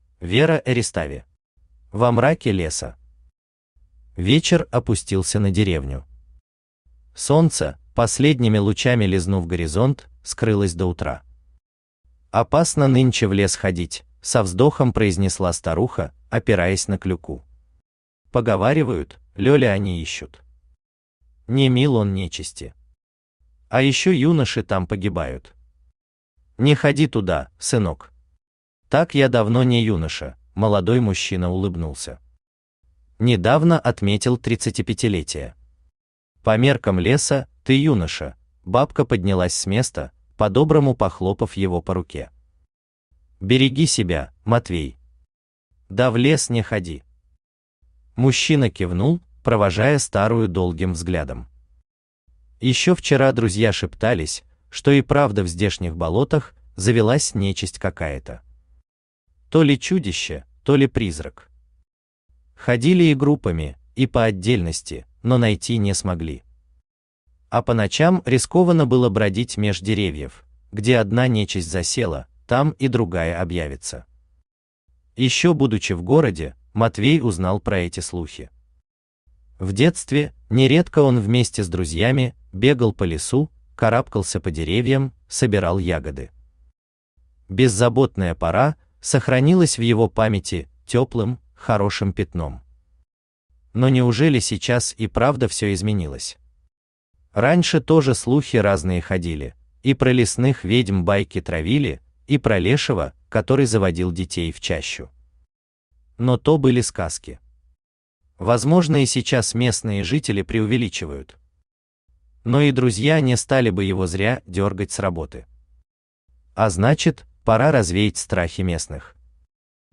Aудиокнига Во мраке леса Автор Вера Эристави Читает аудиокнигу Авточтец ЛитРес.